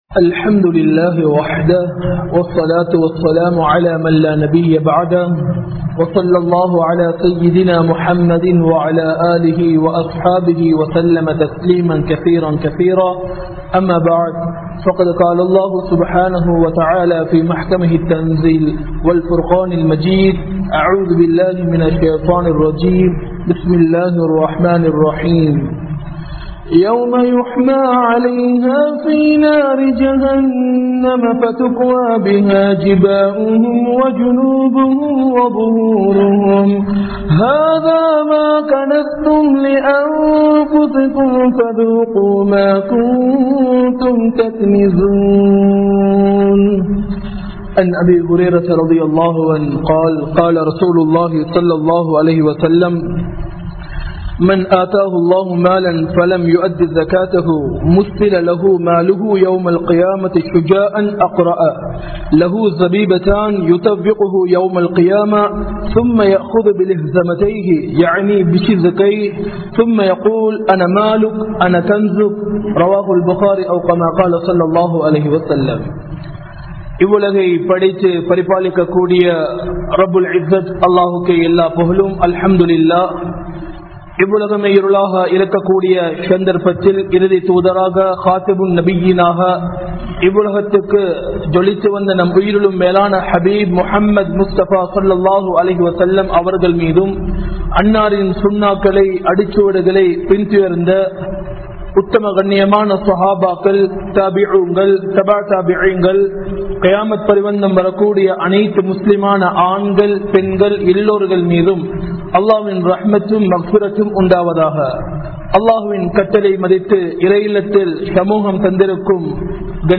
Porulathaara Veelchium Zakathum (பொருளாதார வீழ்ச்சியும் ஸகாத்தும்) | Audio Bayans | All Ceylon Muslim Youth Community | Addalaichenai
Majma Ul Khairah Jumua Masjith (Nimal Road)